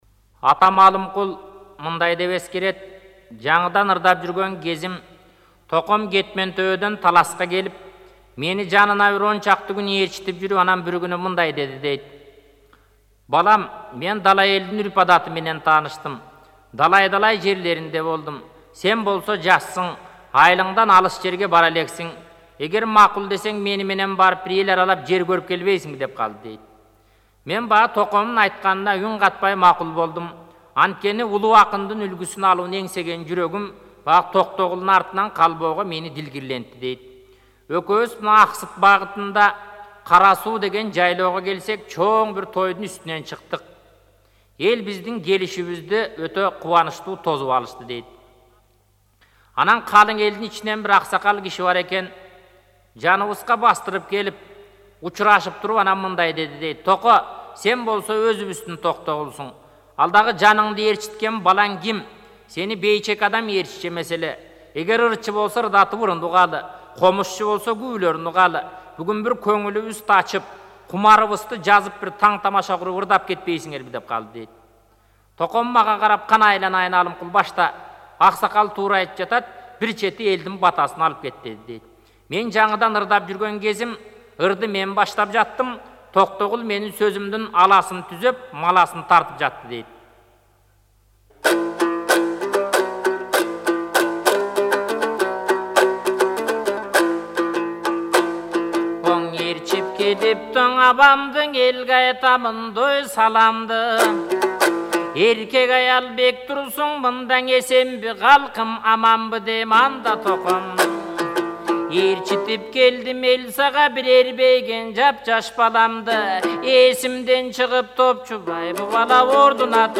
Комуз ырлары